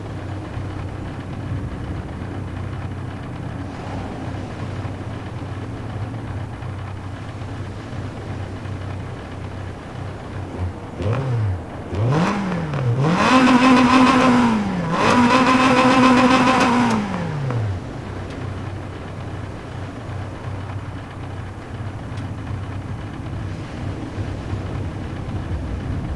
idle.wav